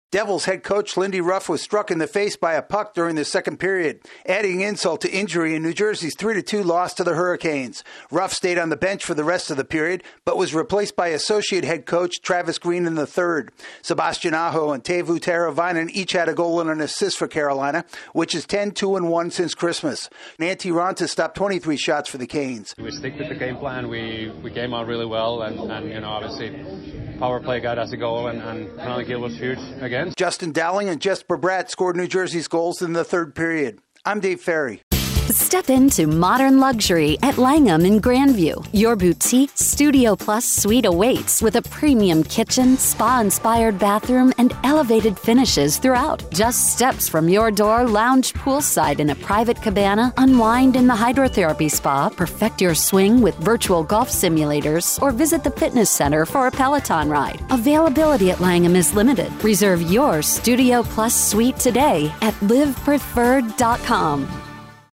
The Hurricanes contnue their post-Christmas hot streak. AP correspondent